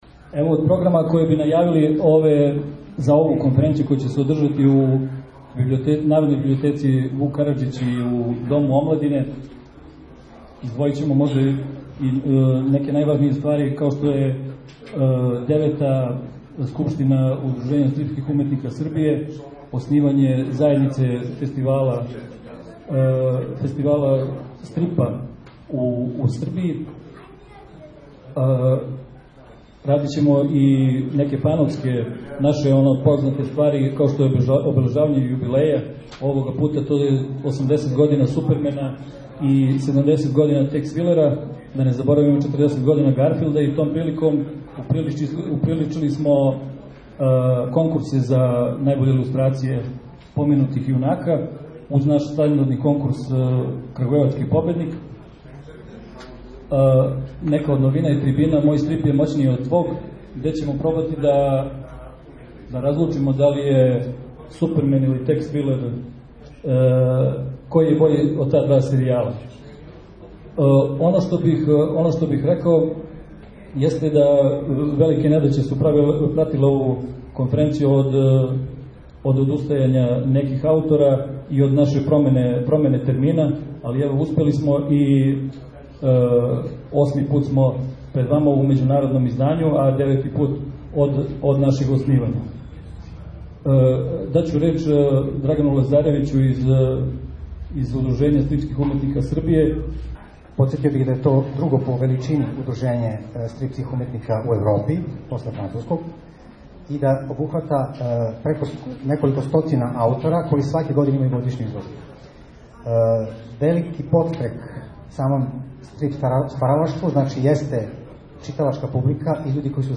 Међународна стрип конференција „Крагујевац, године осме“ одржана је у Народној библиотеци „Вук Караџић“ и Дому омладине.